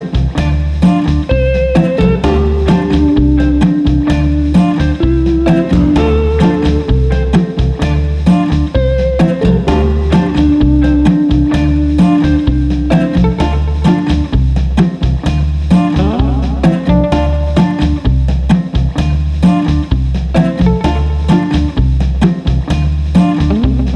Duet Version Pop (2010s) 4:03 Buy £1.50